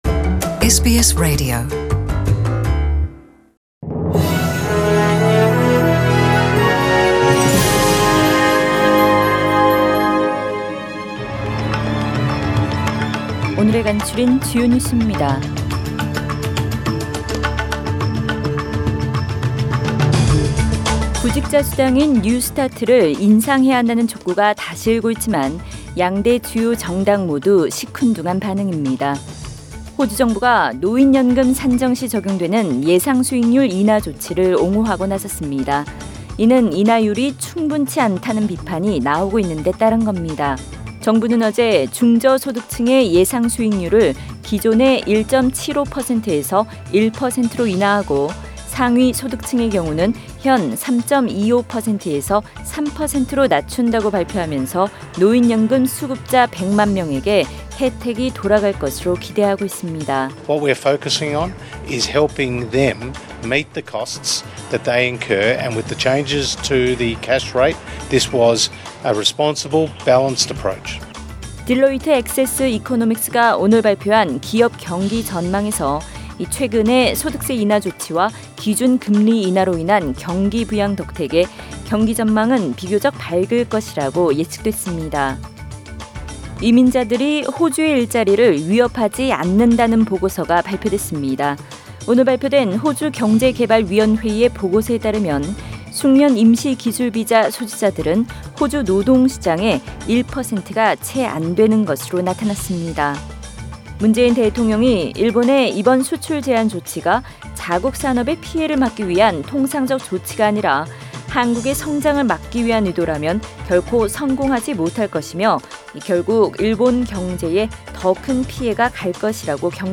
2019년 7월 15일 월요일 저녁의 SBS Radio 한국어 뉴스 간추린 주요 소식을 팟 캐스트를 통해 접하시기 바랍니다.